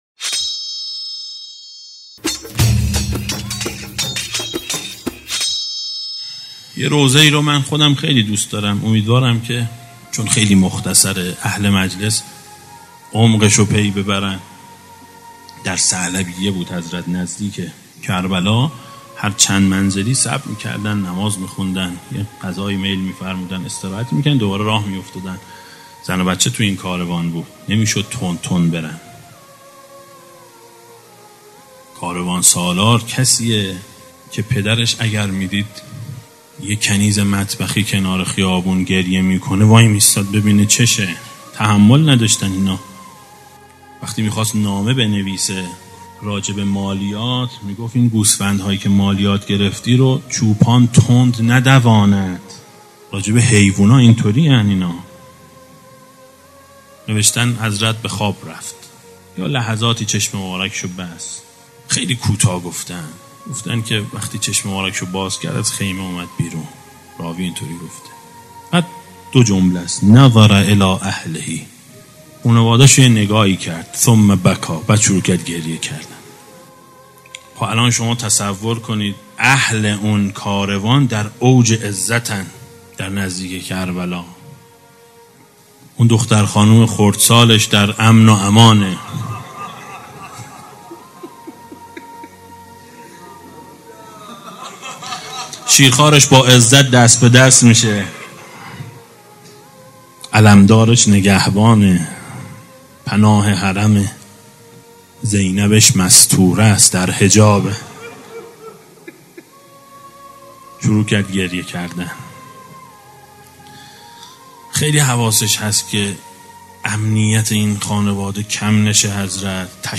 روضه شب دوم محرم سال 1394